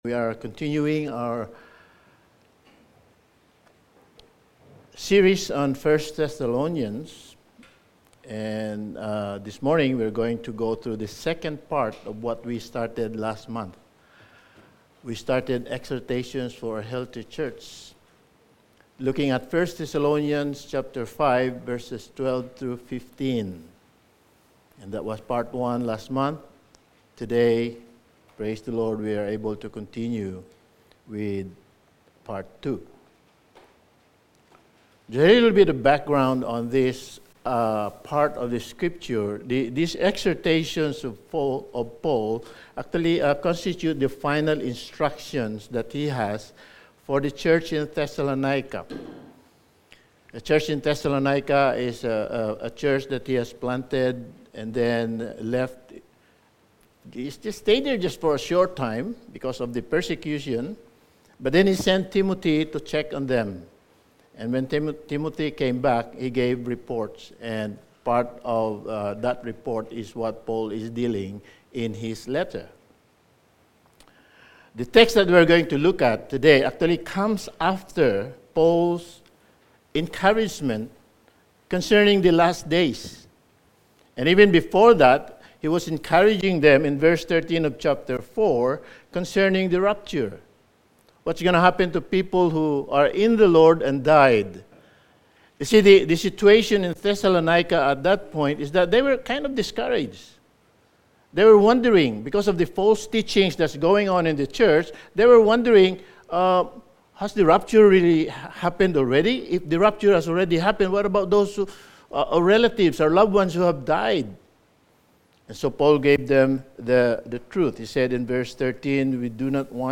Sermon
Service Type: Sunday Morning Sermon